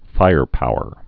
(fīrpouər)